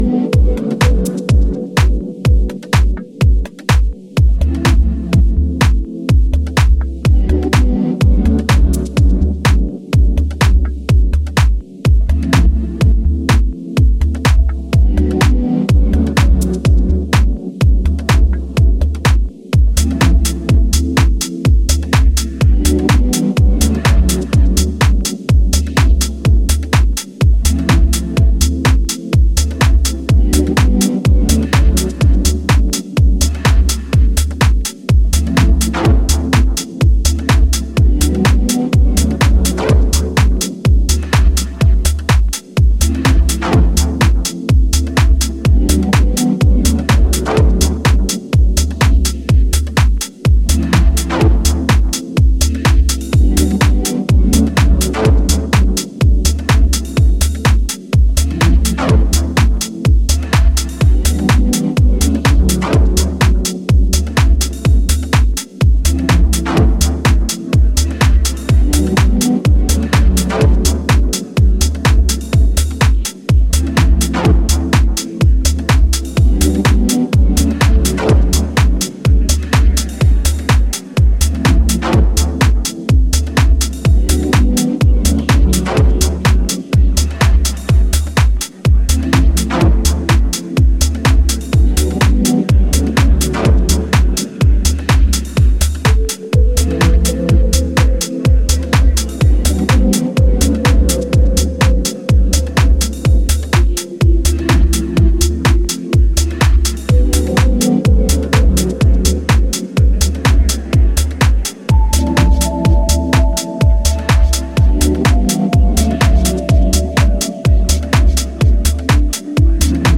Here we have three super-deep yet driving cuts